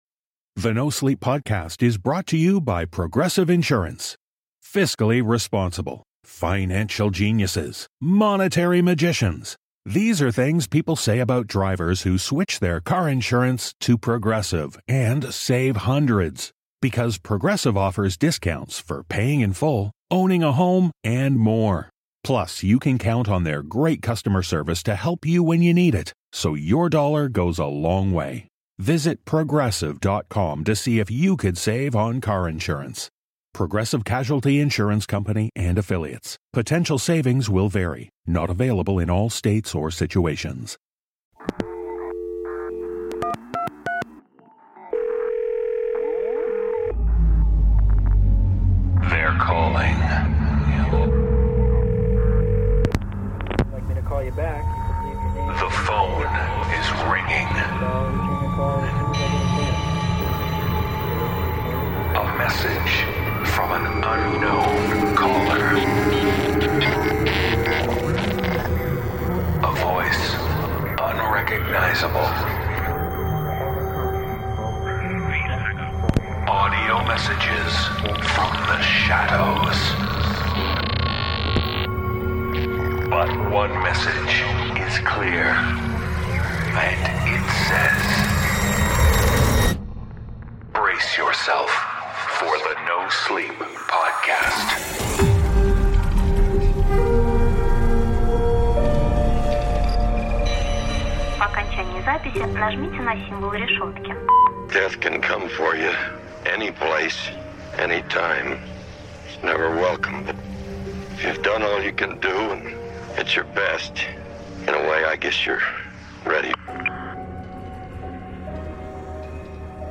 The voices are calling with tales about reaping grimly.